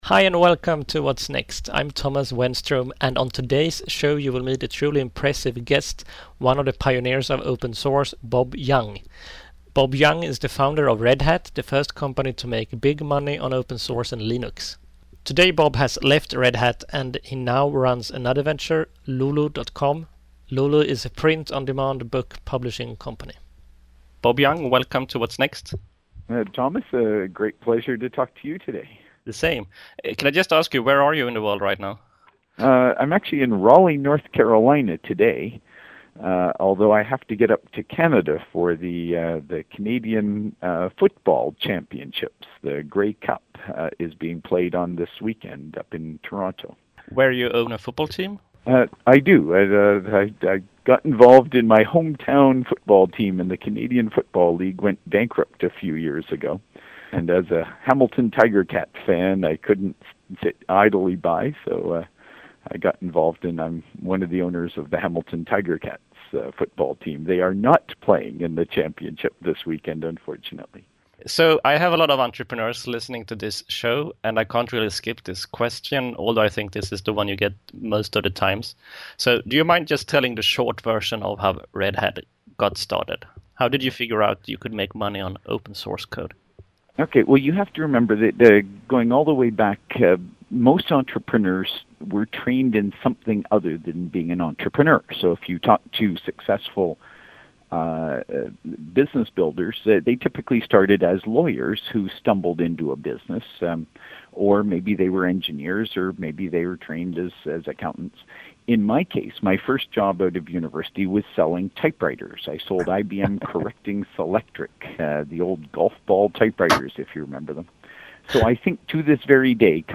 Throughout the interview Bob Young give loads of advice to entrepreneurs.
I apologize for the Skype-recording getting slightly out of sync at the end.